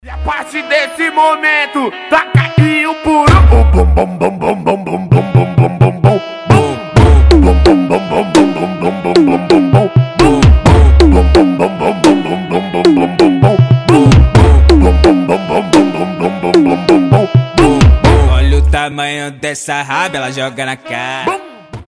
• Качество: 320, Stereo
ритмичные
веселые
Фанк
реггетон
Заводная португальская нарезка